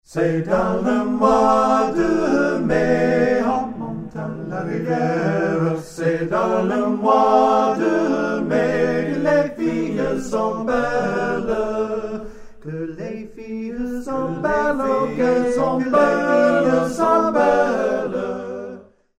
Sung a capella